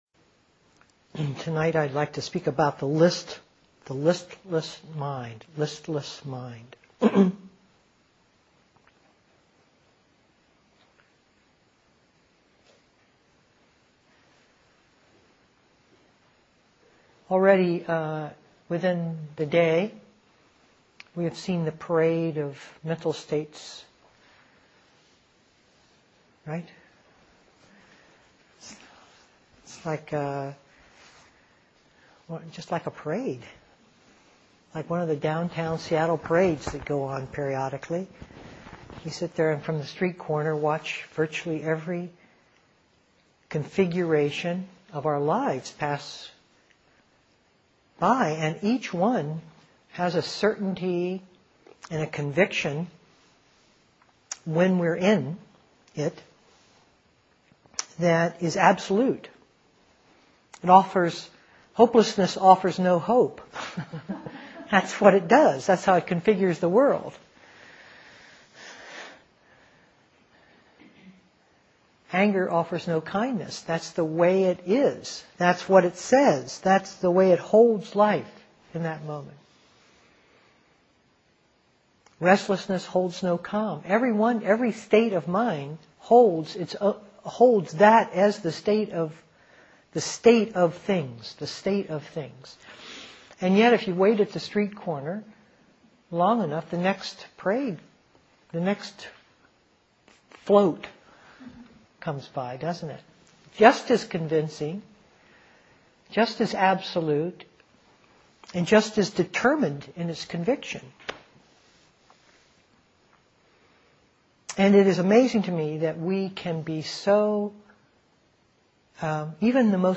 2007-09-07 Venue: Seattle Insight Meditation Center